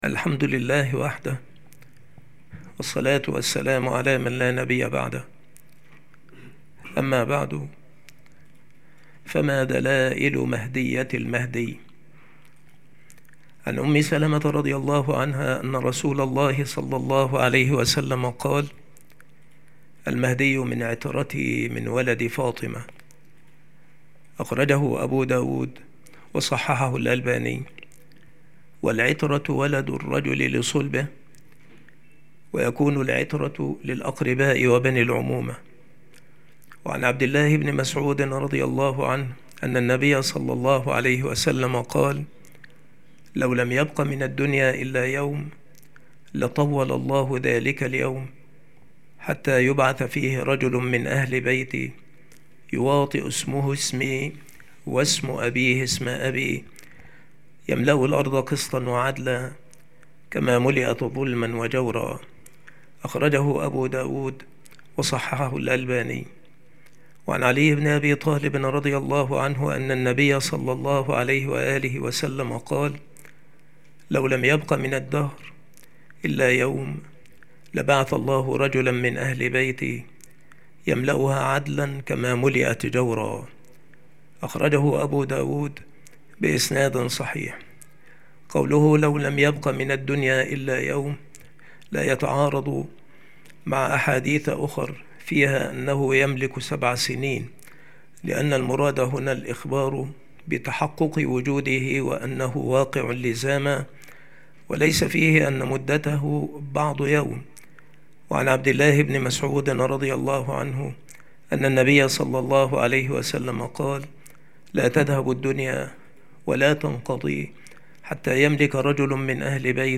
مكان إلقاء هذه المحاضرة المكتبة - سبك الأحد - أشمون - محافظة المنوفية - مصر